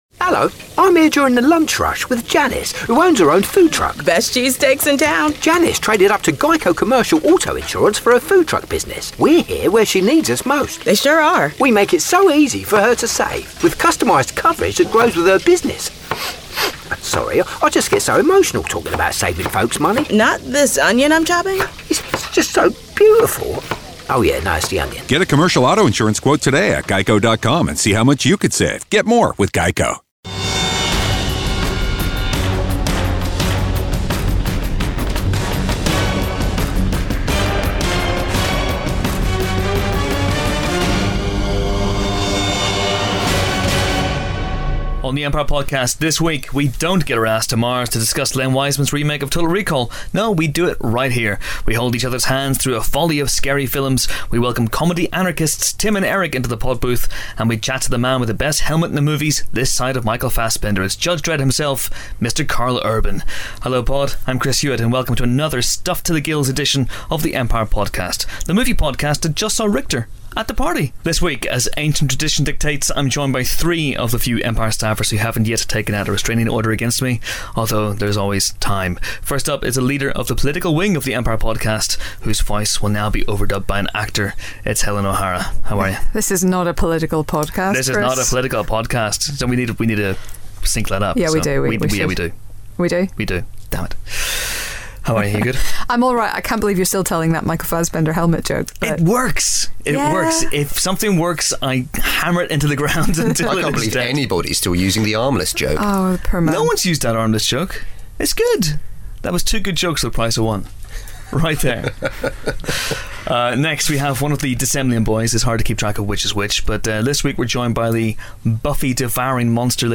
Following on from his appearance on our special Comic-Con podcasts earlier in the year, Karl Urban stops by the podbooth to (amongst other things) recreate Singin' In The Rain using his Judge Dredd voice. Elsewhere, Tim and Eric - of Tim and Eric Awesome Show, Great Job fame - talk about their feature debut, Tim and Eric's Billion Dollar Movie, and the podcast team review Total Recall, A Few Best Men and Cockneys Vs. Zombies.